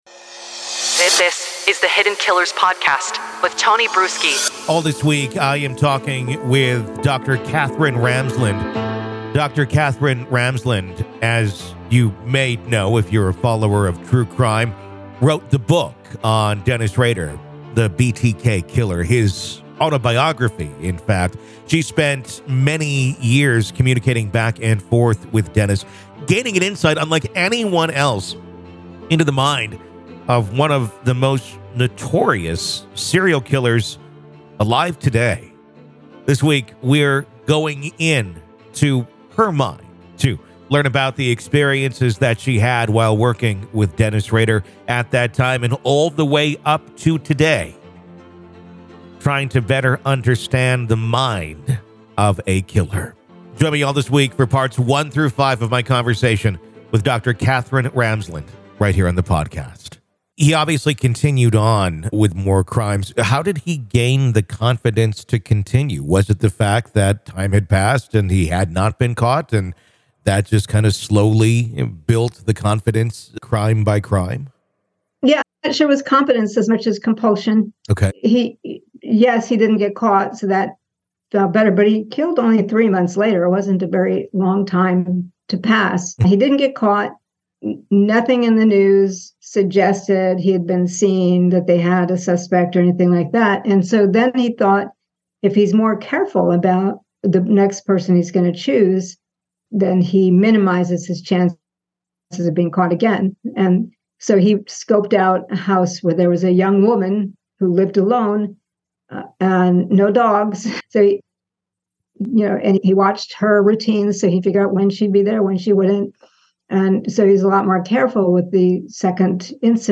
Dr. Katherine Ramsland Interview: Behind The Mind Of BTK Part 4